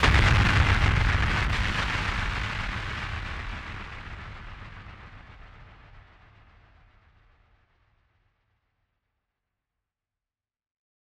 BF_DrumBombC-09.wav